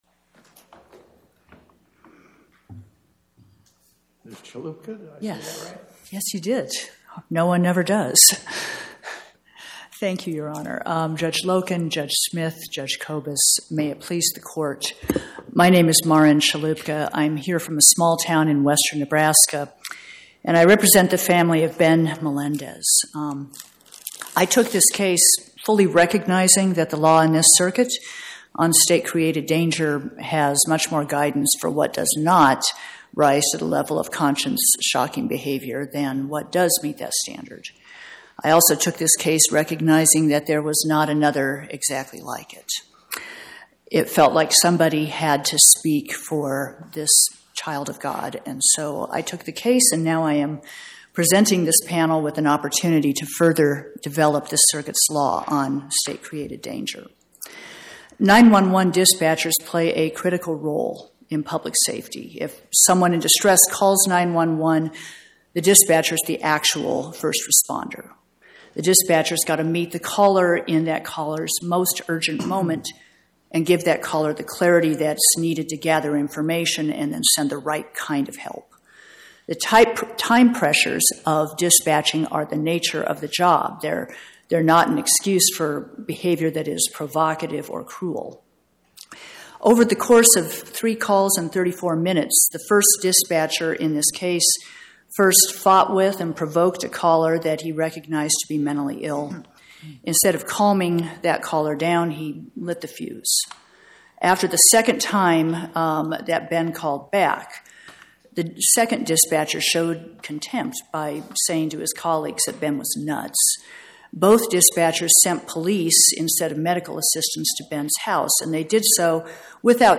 Oral argument argued before the Eighth Circuit U.S. Court of Appeals on or about 12/18/2025